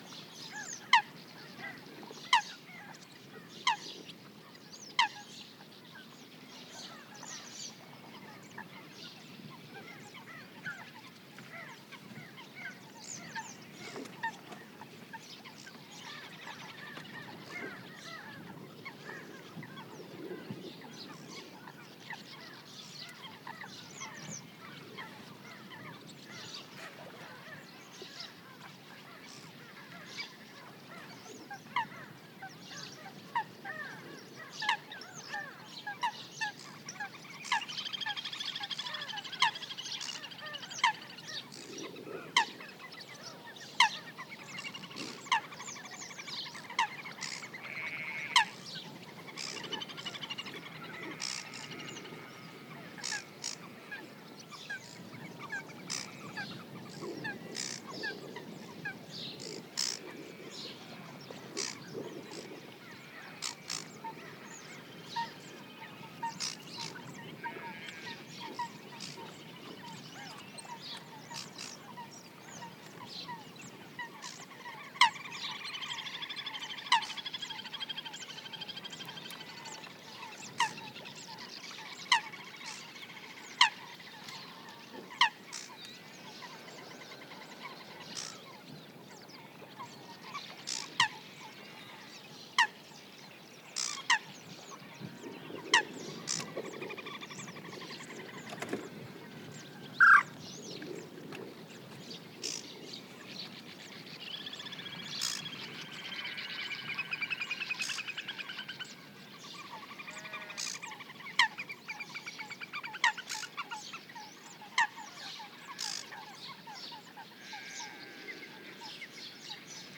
Category 🌿 Nature
ambiance birds common-pochard coot field-recording mallard marshes nature sound effect free sound royalty free Nature